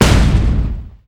rifle1.mp3